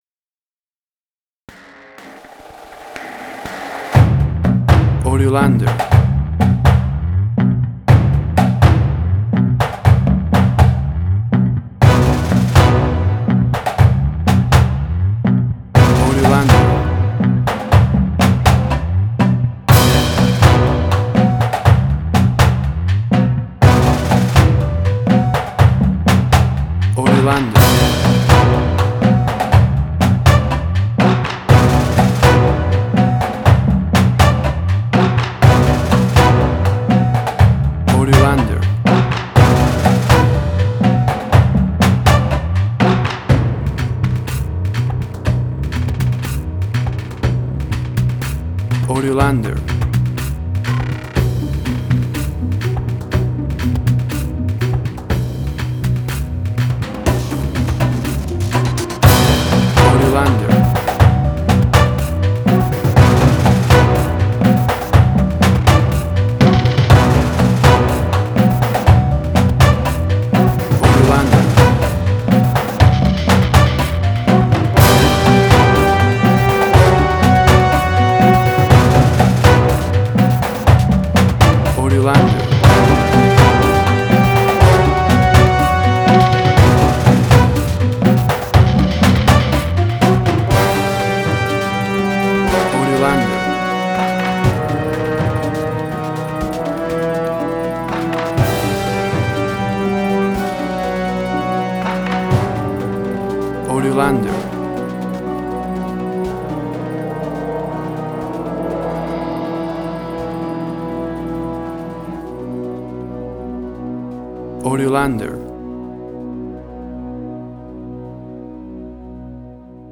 Suspense, Drama, Quirky, Emotional.
Tempo (BPM): 123